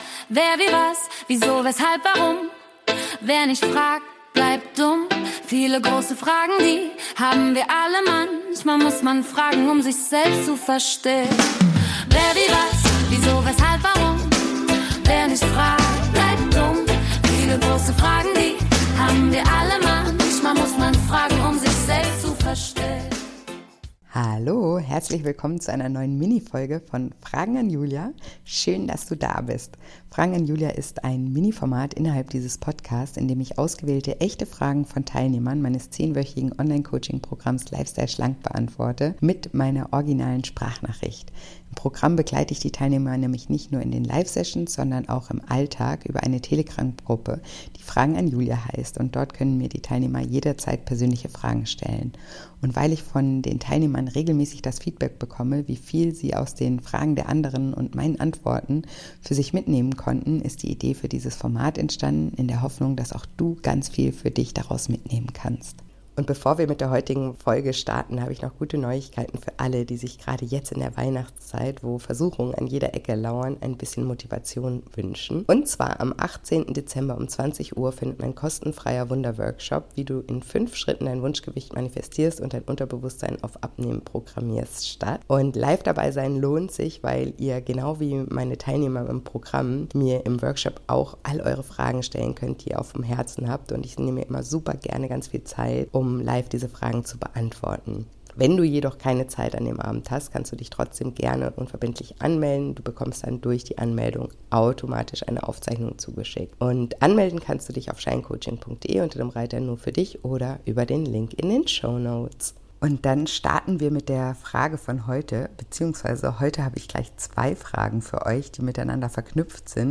Bitte beachte, dass es sich dabei um originale Sprachnachrichten aus dem Coaching-Alltag handelt. Die Audioqualität ist daher nicht wie gewohnt – der Inhalt dafür umso authentischer.